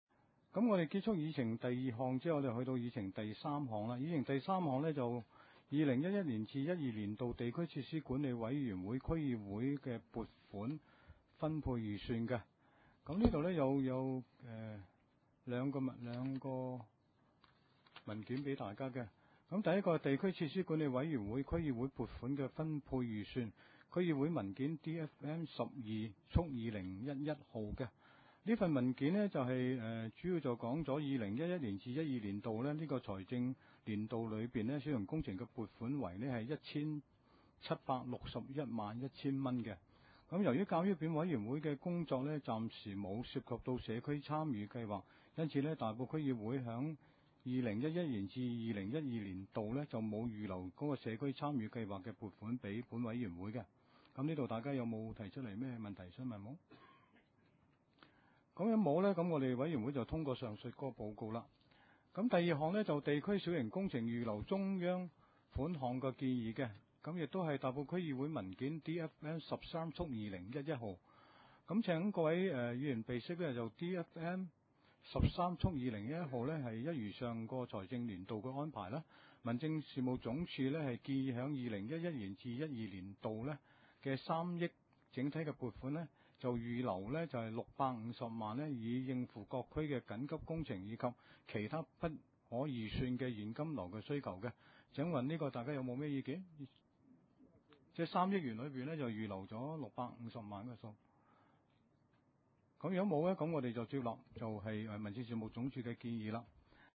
地區設施管理委員會2011年第三次會議
地點：大埔區議會秘書處會議室